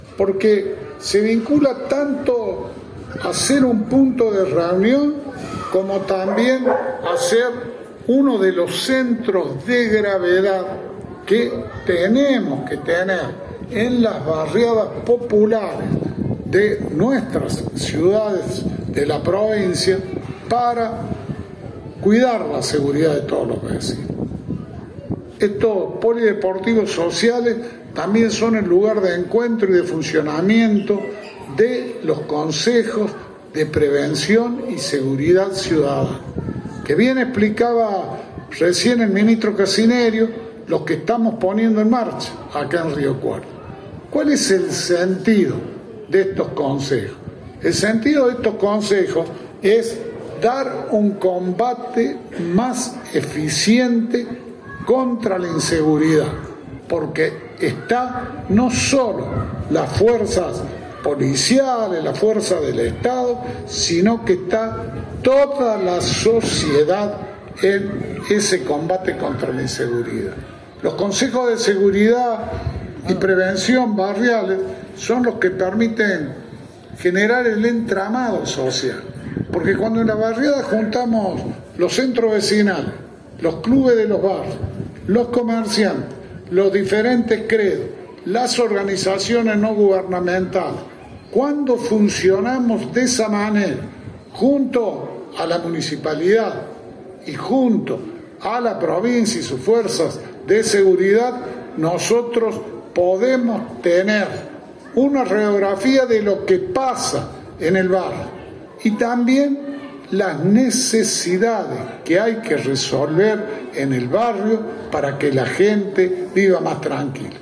Este miércoles, el gobernador Juan Schiaretti inauguró el Polideportivo Social que transformará la vida de las 1.500 familias del barrio San Eduardo, en el suroeste de la ciudad de Río Cuarto.
El gobernador hablando sobre la importancia del polideportivo inaugurado